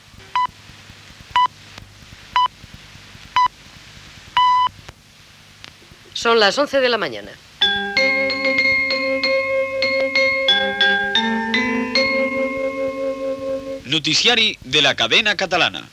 Horàries, hora, sintonia i identificació "Noticiari de la Cadena Catalana".